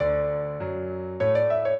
minuet0-9.wav